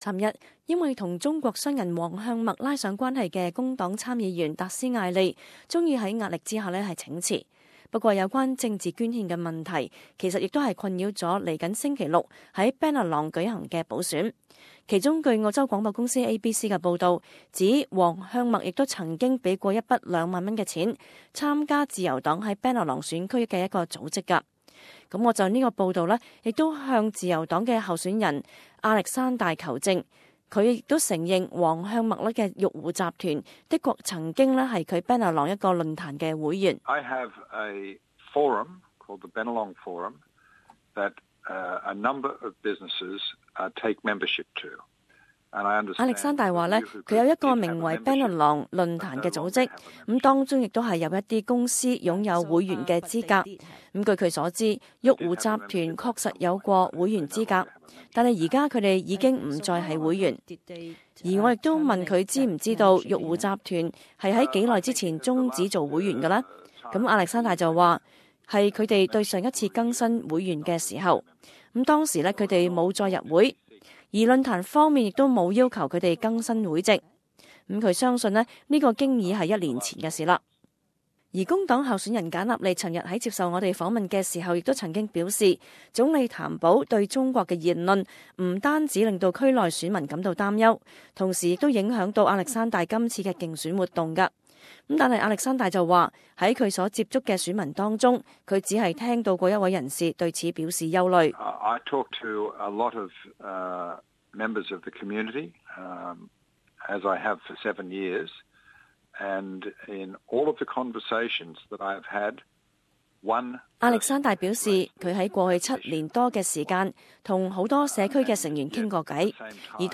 【Bennelong补选特辑】自由党候选人亚历山大专访
这是第二辑与自由党候选人亚历山大的访问。